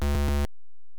Fail sound effect from Super Mario Bros. 3
SMB3_Fail.oga